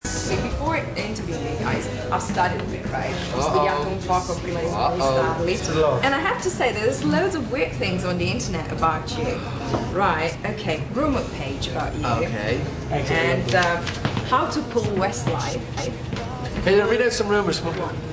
Parts of the interview (the blue ones) are also recorded in MP3 format!!!